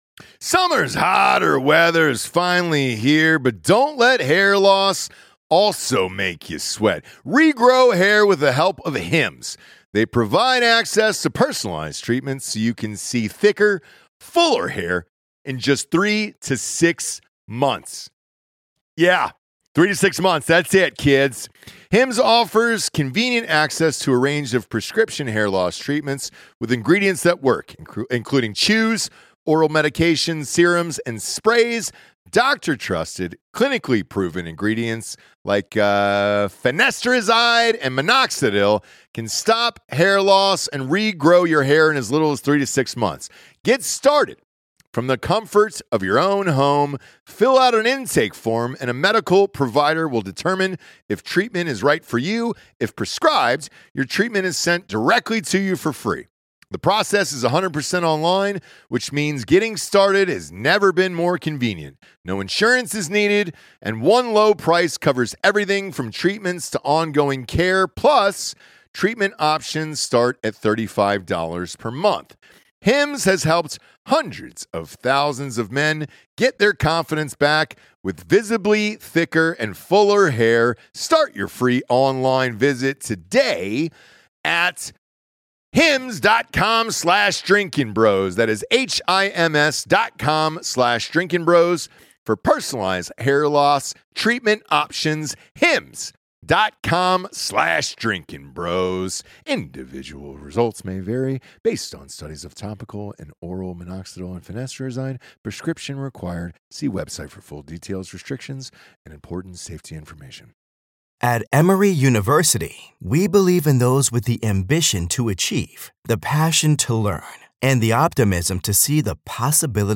recorded live from Shot Show in Las Vegas